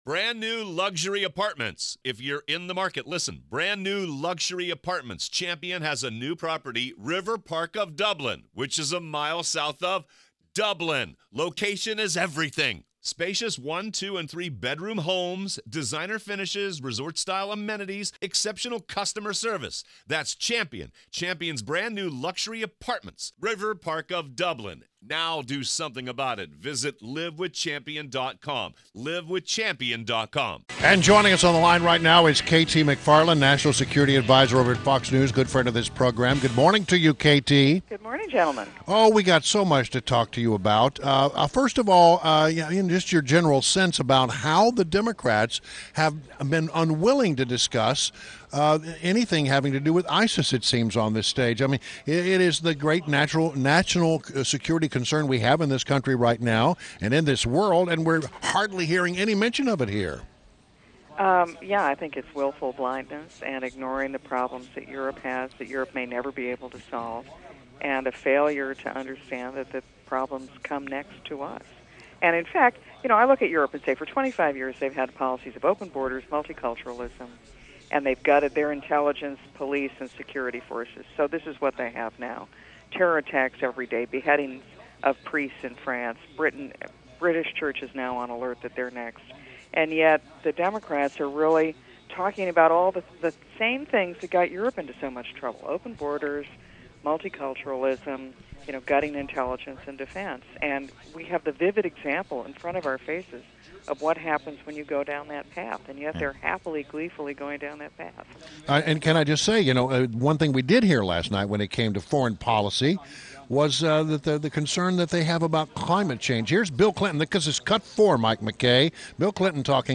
WMAL Interview - KT McFarland - 07.27.16